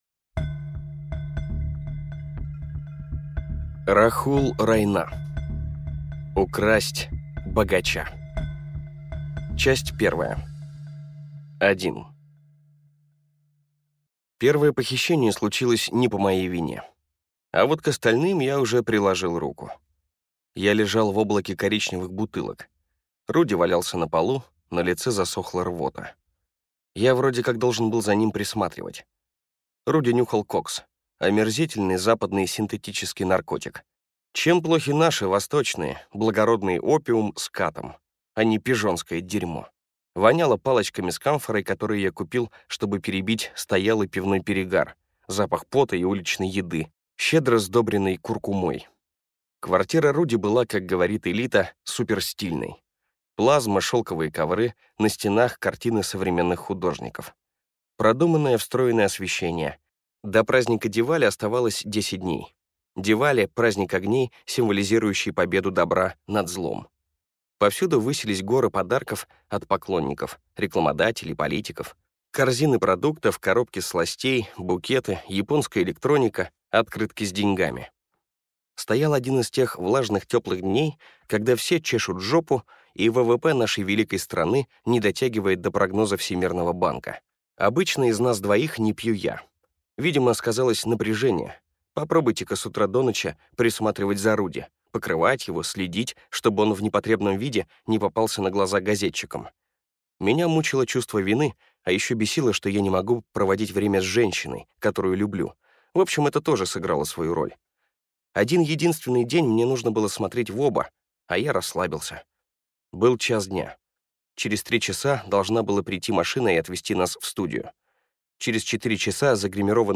Аудиокнига Украсть богача | Библиотека аудиокниг